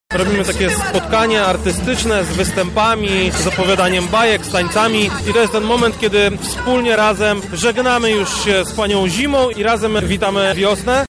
Wiosna zagościła na starówce w Lublinie.
Więcej mówi Krzysztof Komorowski, zastępca prezydenta Lublina: